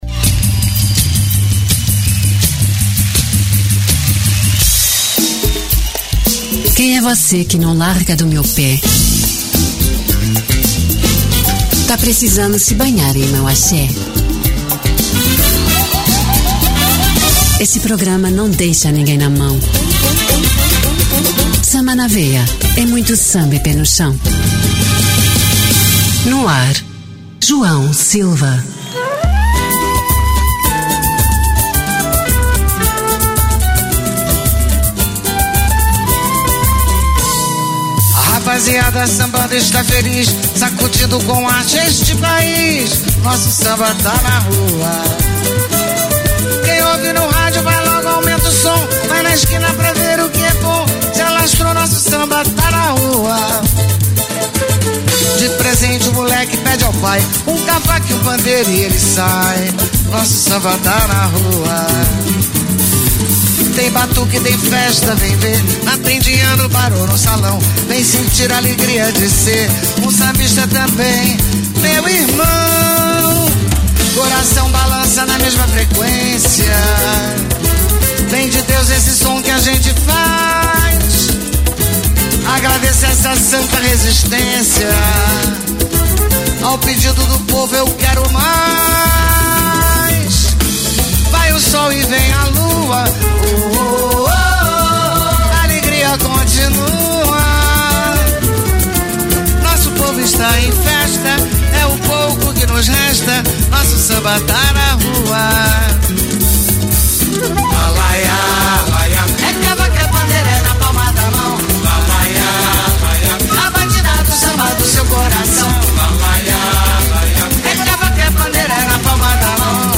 Samba de Raíz